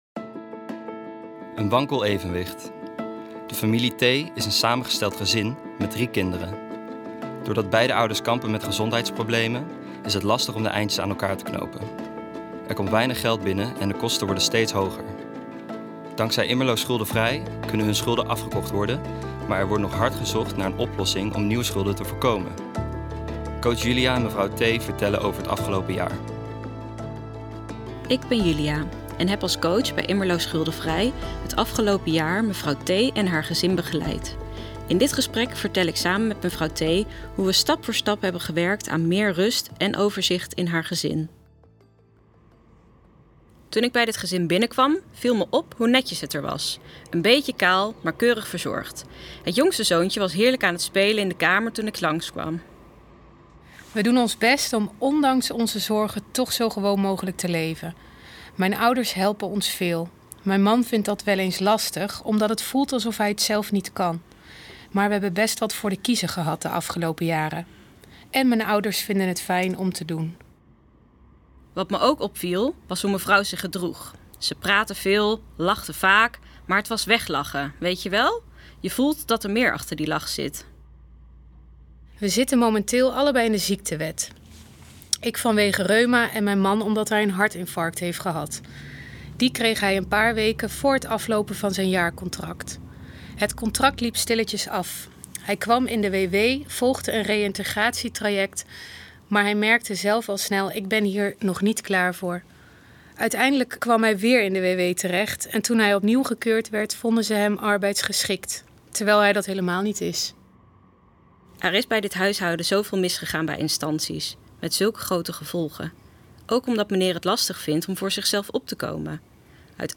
De verhalen worden verteld door stemacteurs.